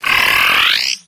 chewtle_ambient.ogg